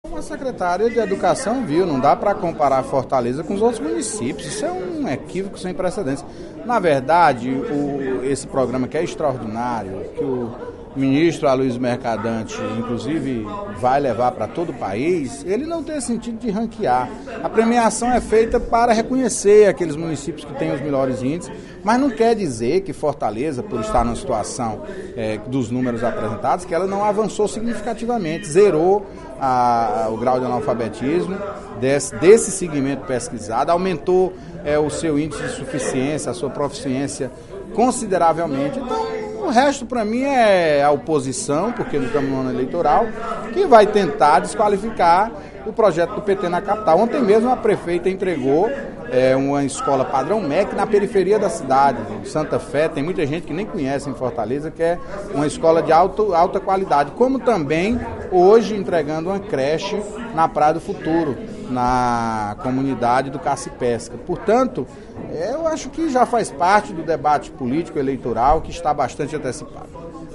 O líder do Governo na Casa, deputado Antonio Carlos (PT), rebateu nesta terça-feira (22/05) em sessão plenária, críticas de alguns parlamentares em relação ao desempenho da rede pública de Fortaleza no Sistema Permanente de Avaliação da Educação Básica (Spaece-Alfa) 2011 – divulgado ontem pelo Governo do Estado.